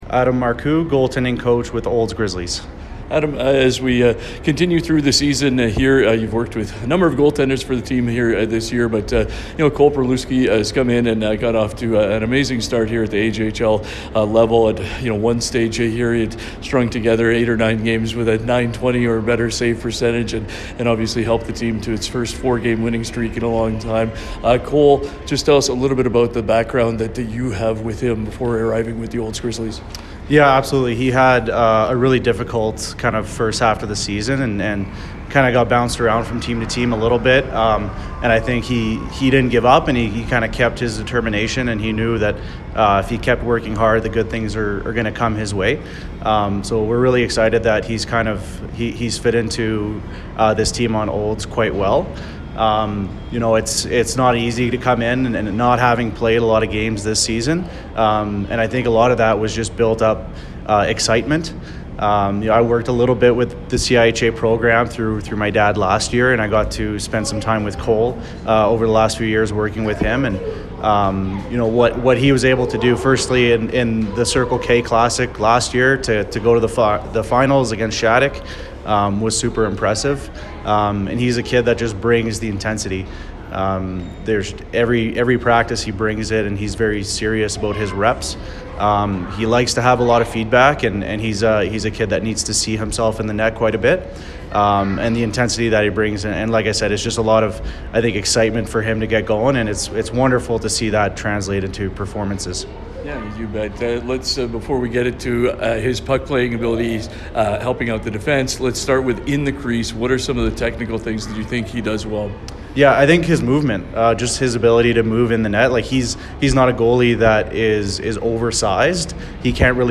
pre-game chat